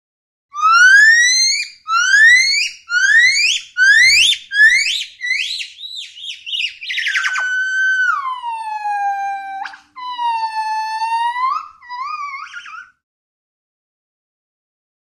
GibbonCallLoudWail AT052001
Gibbon Call. Loud Wailing Call Ascending And Descending In Pitch. Close Perspective.